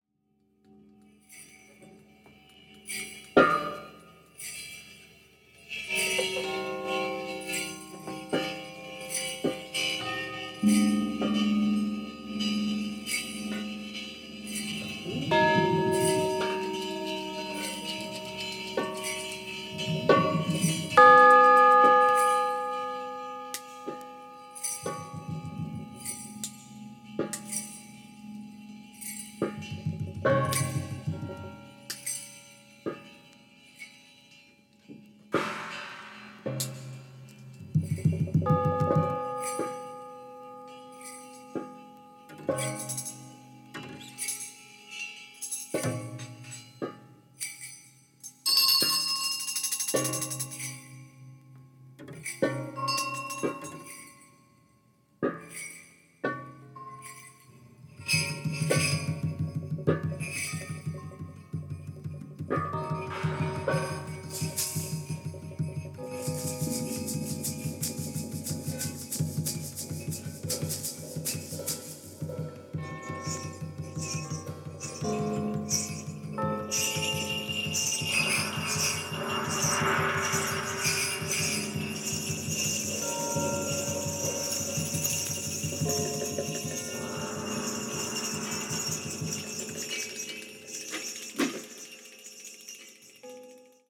76年シカゴで録音されたヤツです。